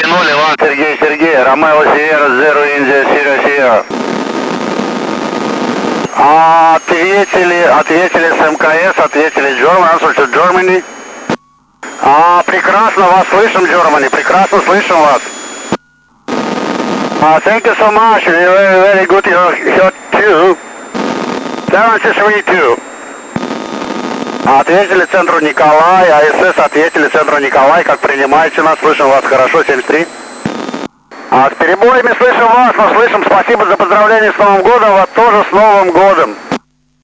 Начало » Записи » Записи радиопереговоров - МКС, спутники, наземные станции
Обмен экипажа МКС с радиолюбителями 145.800Мгц, 20.12.2014 ок.16-00UTC